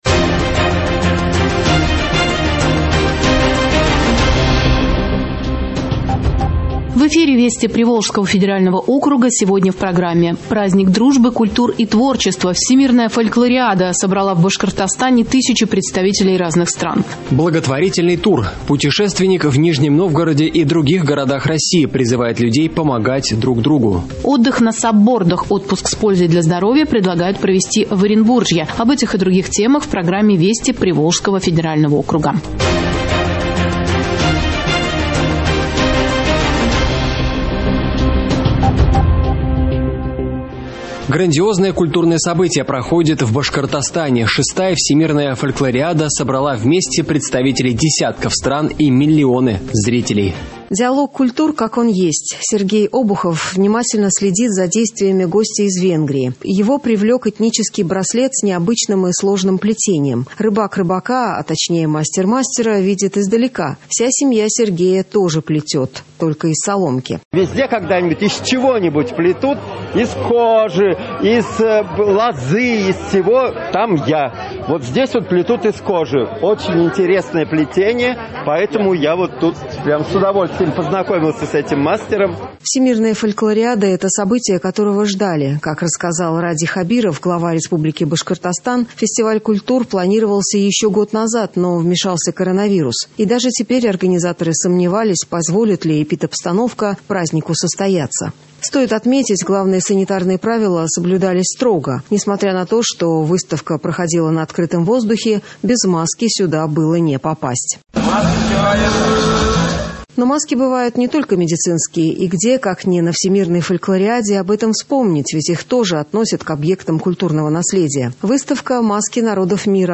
Радиообзор событий в регионах ПФО.